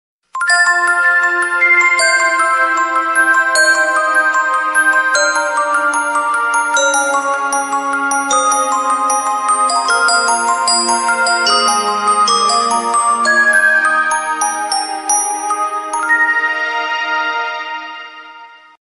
добрые
волшебство
Красивая мелодия колокольчиков для вашего телефона